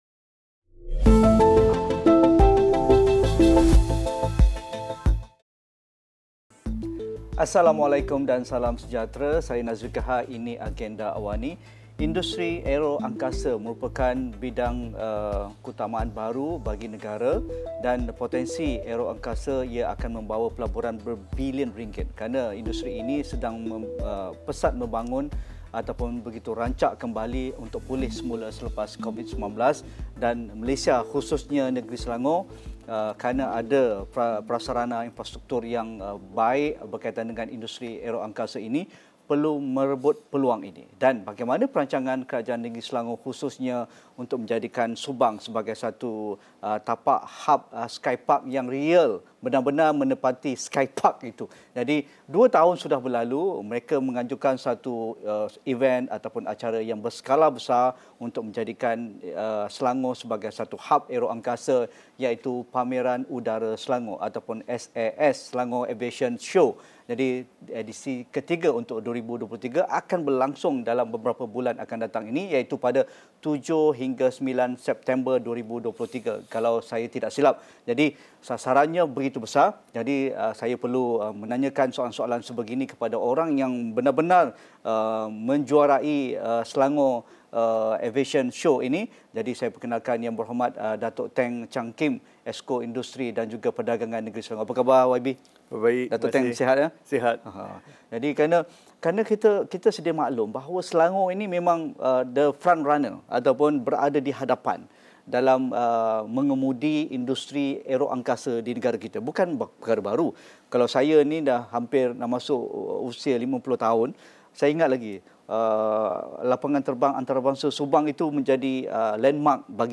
Pameran Penerbangan Selangor (SAS) 2023 kembali dengan edisi ketiga, namun sejauh mana ia dilihat signifikan sebagai pencetus kebangkitan semula industri aeroangkasa? Diskusi 9 malam